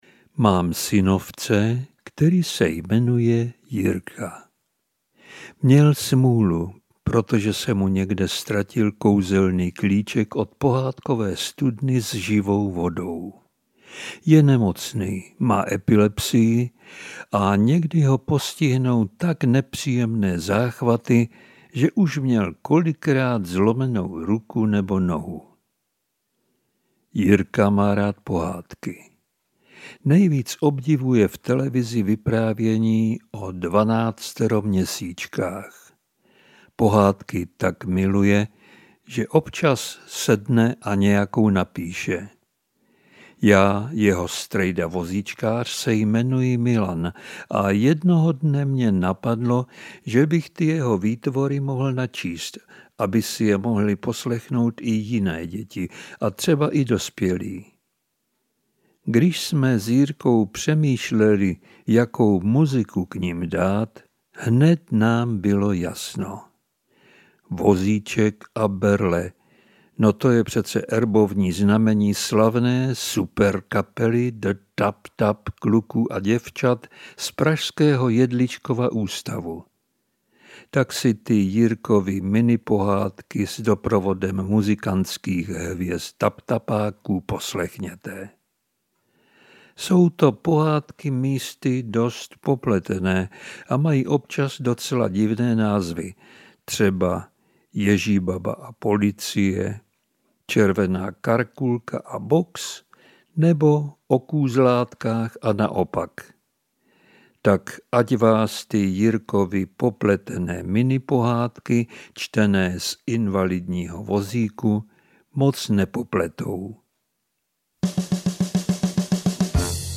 Ukázka z knihy
Proto u každé minipohádky uslyšíte vždy jednu písničku superskupiny The Tap Tap kluků a děvčat z pražského Jedličkova ústavu.